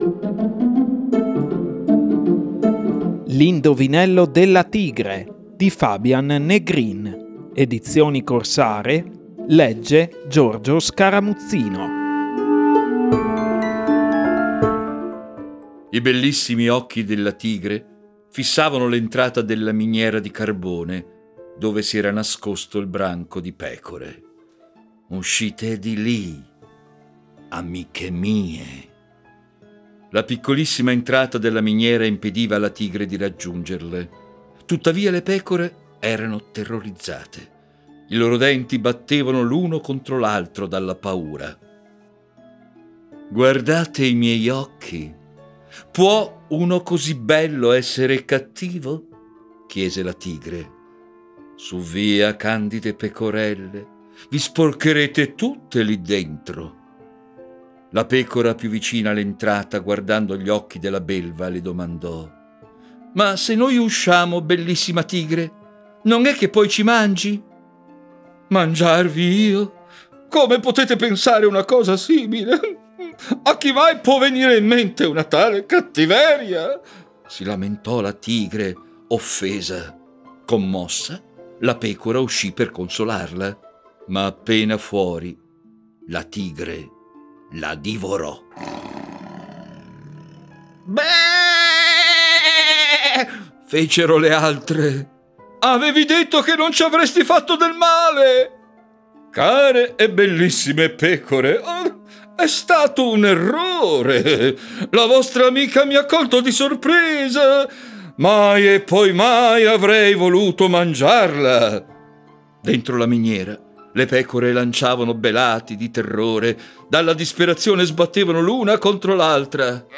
- L'indovinello della tigre - con tappeto sonoro